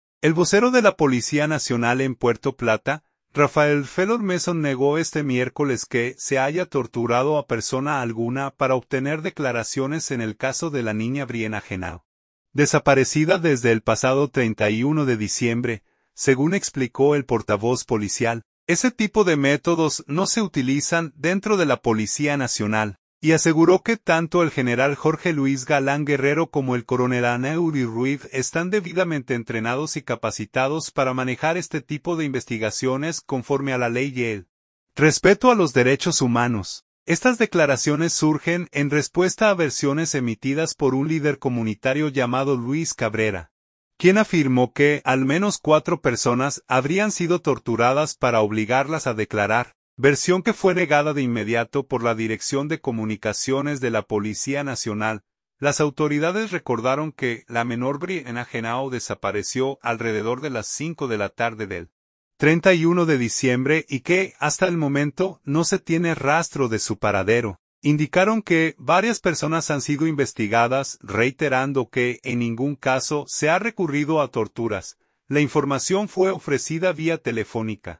La información fue ofrecida vía telefónica.